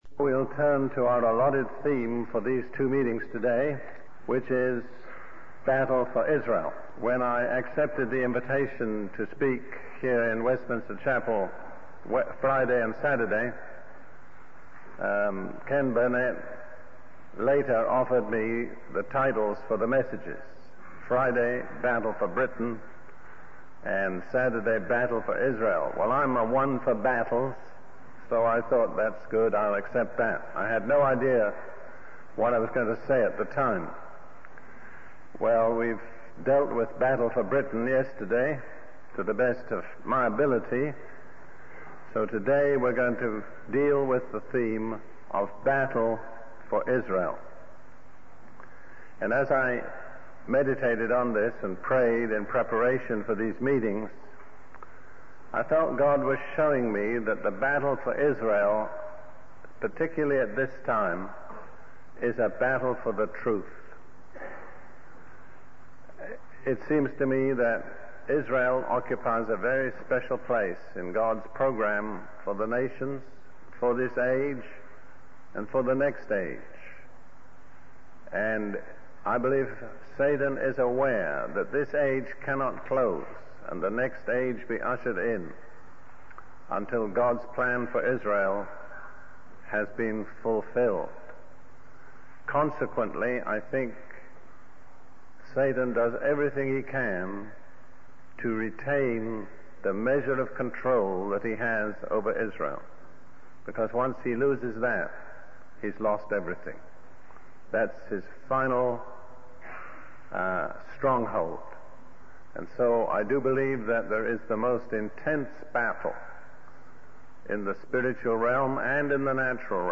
In this sermon, the speaker reflects on the lack of awareness among the people in the church about the troubling events happening around them. He quotes from the book of Isaiah, emphasizing that God is not pleased with their religious activities and sacrifices because their hearts are filled with sin and deceit. The speaker also highlights the need for the church to examine itself and be willing to go through the refining process that God desires in order to become what He intends it to be.